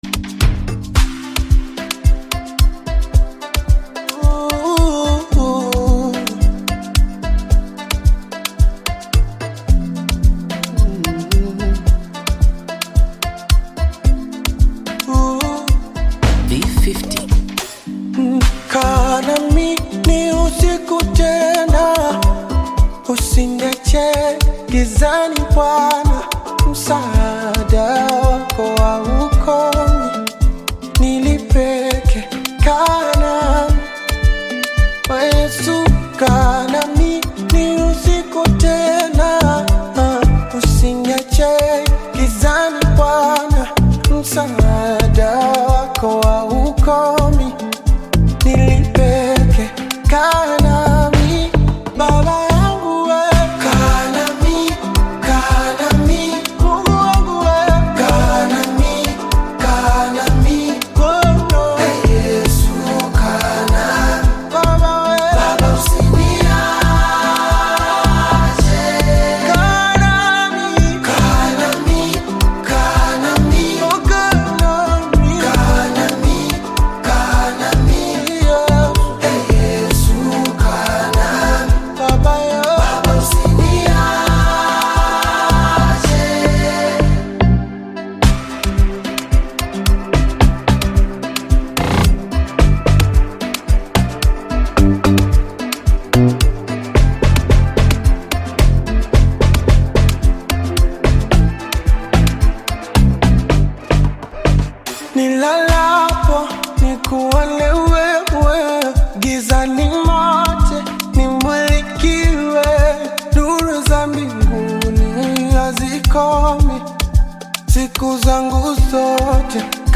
Tanzanian Gospel artist, singer and songwriter
gospel song
African Music